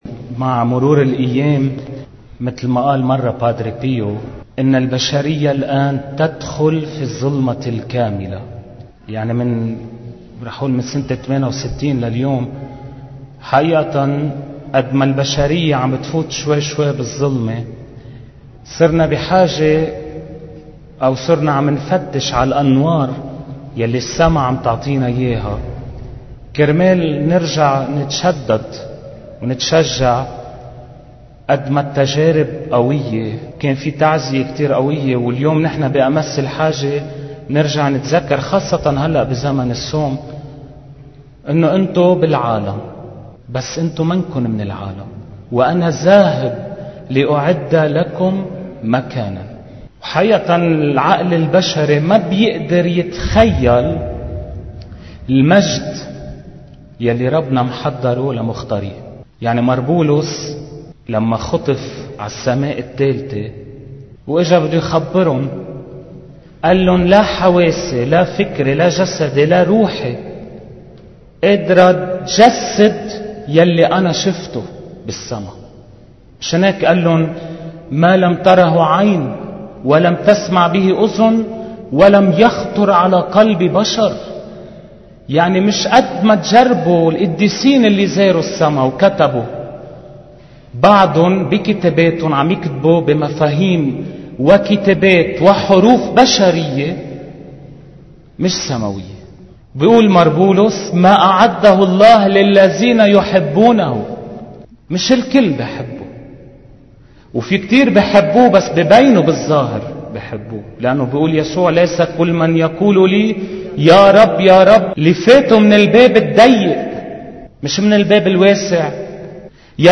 عظة